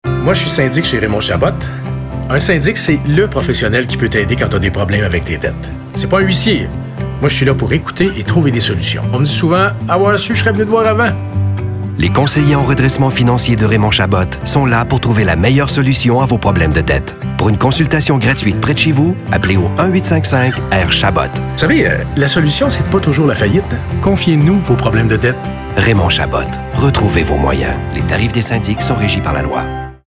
Pour Raymond Chabot, leader de cette industrie au Québec, nous avons plutôt adoptés un ton calme, respectueux et qui invite à la confidence.
Radios